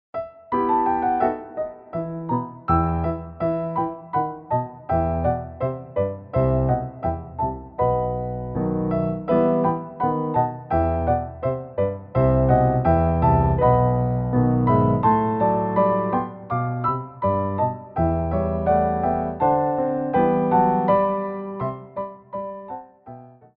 33 Tracks for Ballet Class.
Jetés
4/4 (8x8)